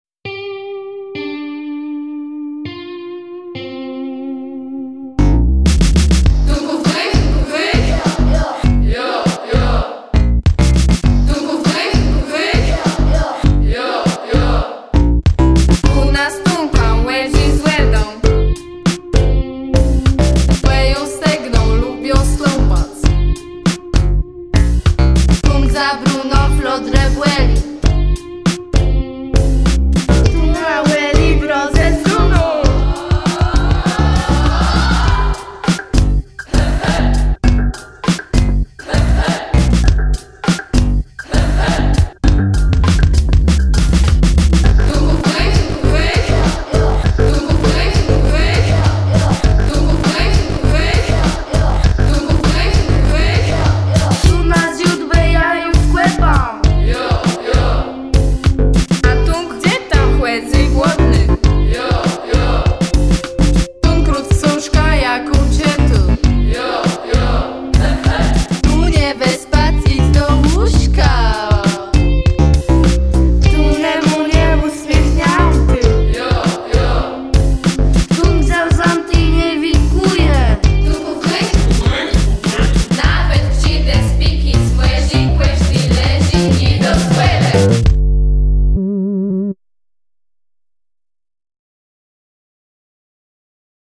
Nagranie wykonania utworu tytuł